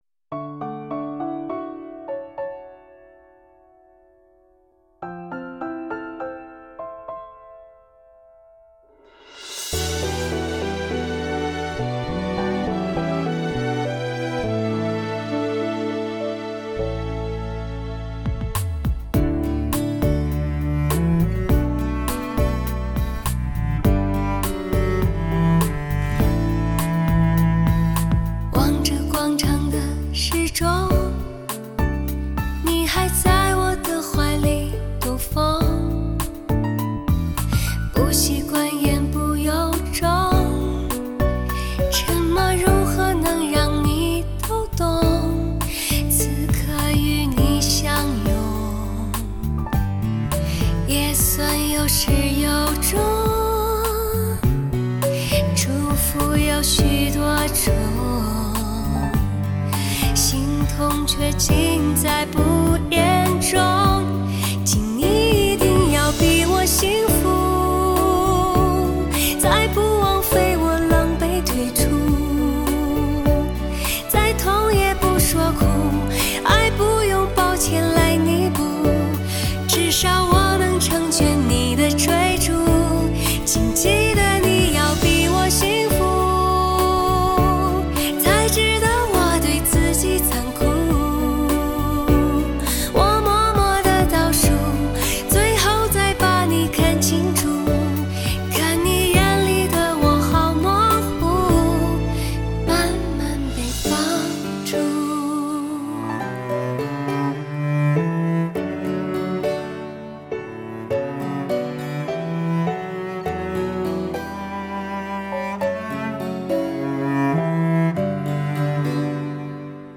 6.1DTS-ES多维空间的环绕音场让你充分享受极端高保真的听感！同时至高享受环绕立体声音响的动感！
听到的是温暖、是柔情、也是伤感，醇厚、沧桑、细腻、浪漫，
低吟浅唱有一股将人骨头变酥的魔力，你感受到了吗？
采用最新美国DTS-ES6.1顶级编码器，创造超乎完美环绕声震撼体验。